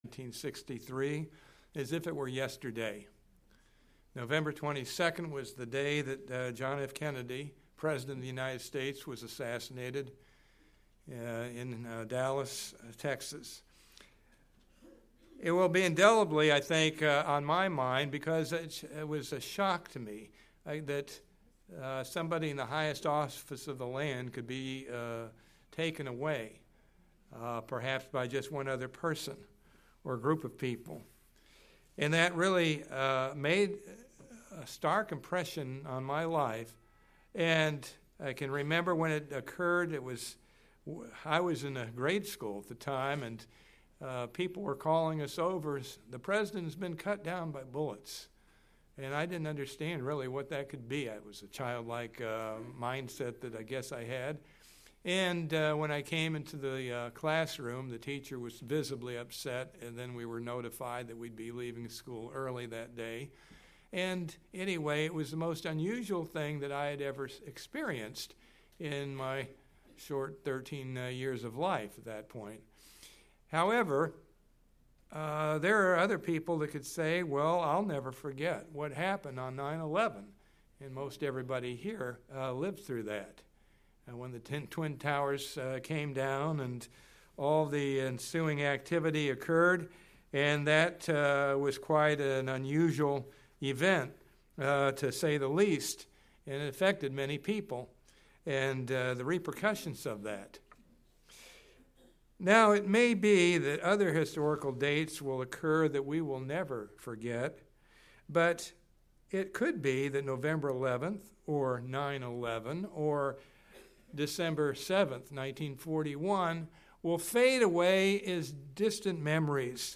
There is going to come a day when we will say I remember what it was like when Christ returned. In this Sermon you will learn what God has built into all of us.
Given in St. Petersburg, FL